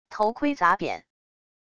头盔砸扁wav音频